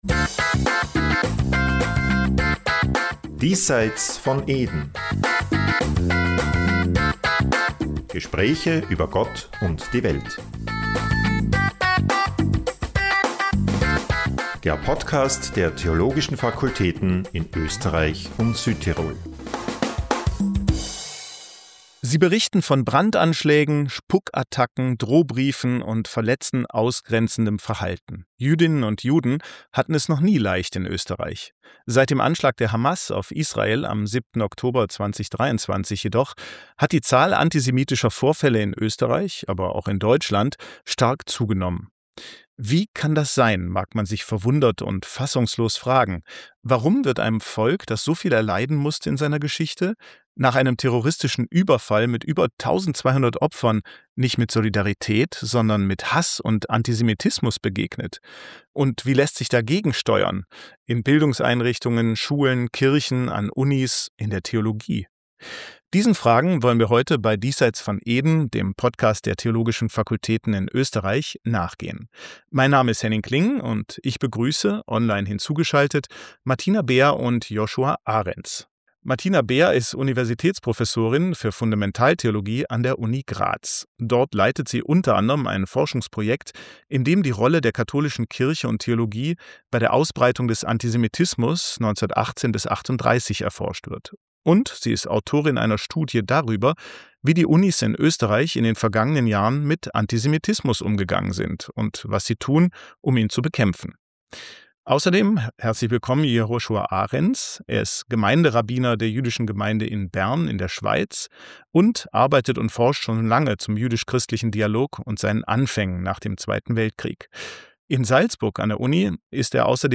online hinzugeschaltet